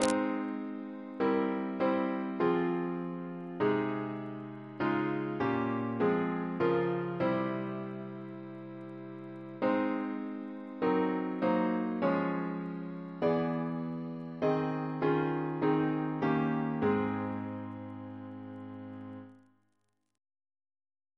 Double chant in A♭ Composer: George Mursell Garrett (1834-1897), Organist of St. John's College, Cambridge Reference psalters: ACP: 268; CWP: 10; H1982: S193 S244; RSCM: 54